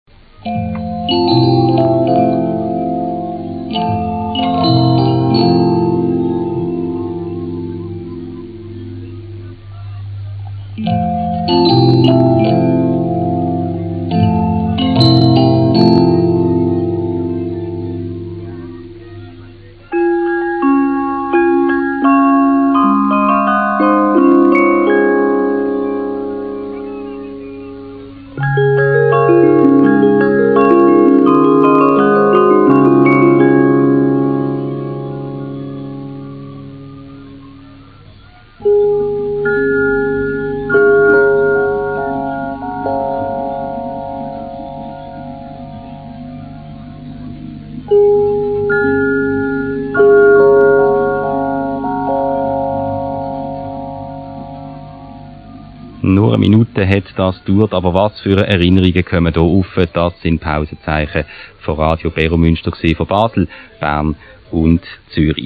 Radiomuseum Pausenzeichen